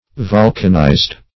Search Result for " volcanized" : The Collaborative International Dictionary of English v.0.48: Volcanize \Vol"can*ize\, v. t. [imp.
volcanized.mp3